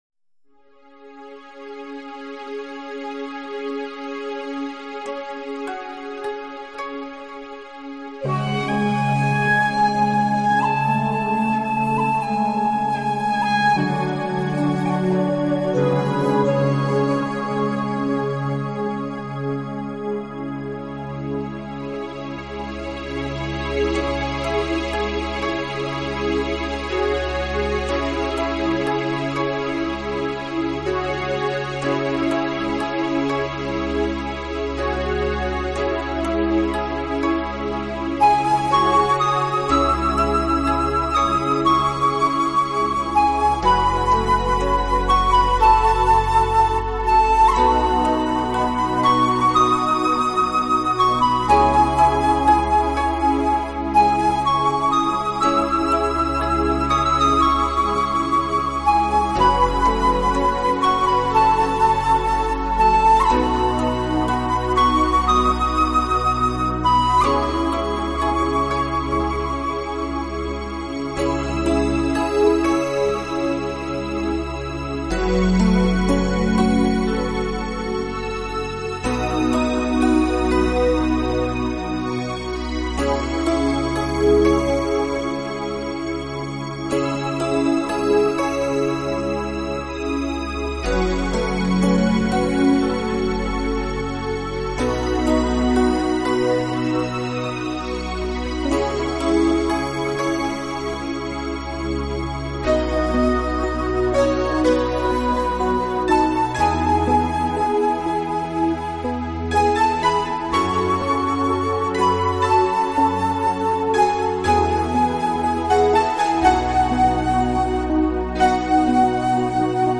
配器构架出没有压力，没有负担的乐曲，加之高超的录音技术，使得音乐具有空灵感，
它不只是新世纪音乐，更是取自
合成乐音为演奏的主体，再结合他们所采撷的大自然音效，在这两相结合之下，您可
器配置，使每首曲子都呈现出清新的自然气息。